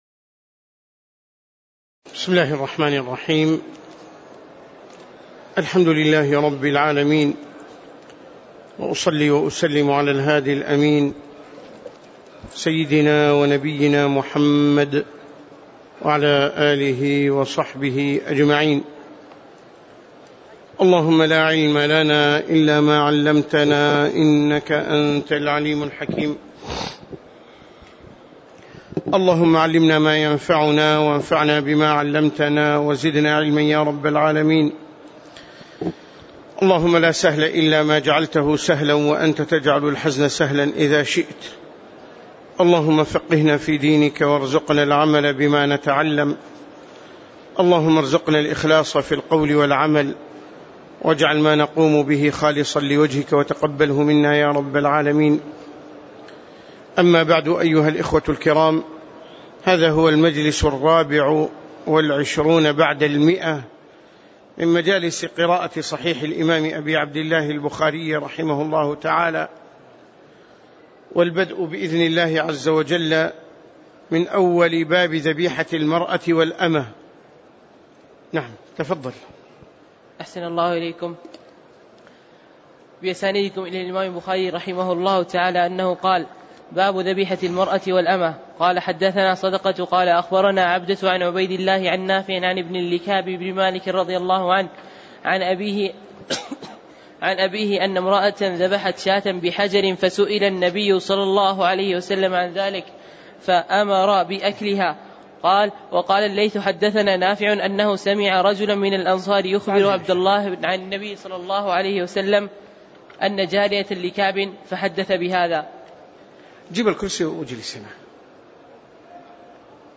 تاريخ النشر ٢٦ شعبان ١٤٣٨ هـ المكان: المسجد النبوي الشيخ